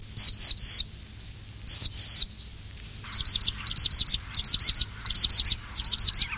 Chevalier guignette
Actitis hypoleucos